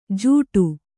♪ jūṭu